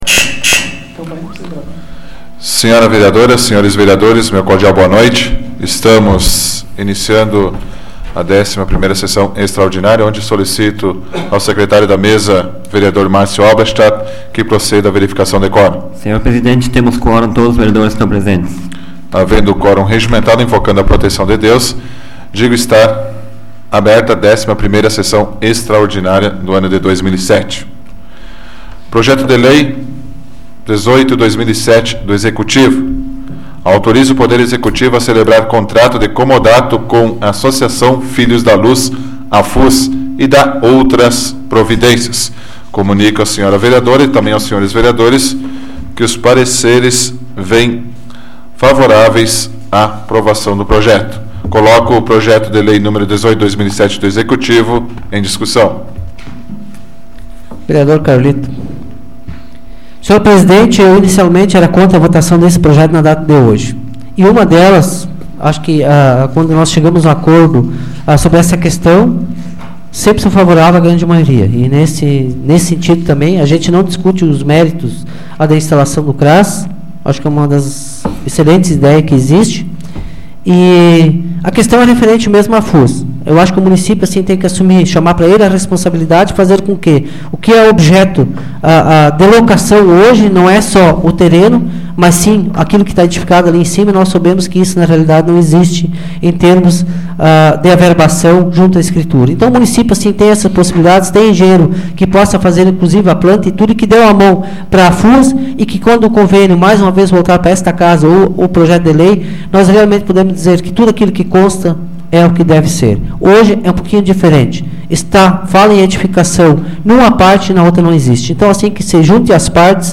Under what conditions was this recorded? Áudio da 41ª Sessão Plenária Extraordinária da 12ª Legislatura, de 03 de setembro de 2007